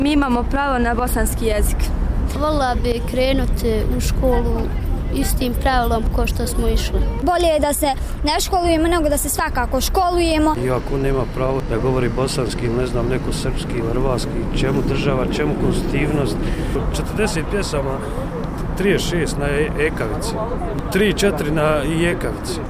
Djeca i roditelji na protestu